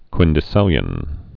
(kwĭndĭ-sĭlyən)